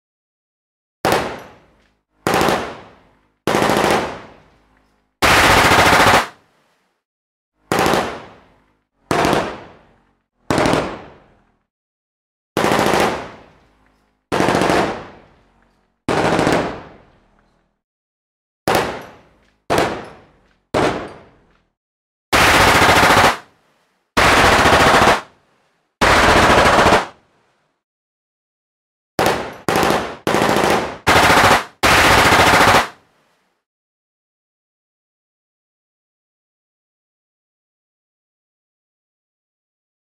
جلوه های صوتی
دانلود صدای رگبار 1 از ساعد نیوز با لینک مستقیم و کیفیت بالا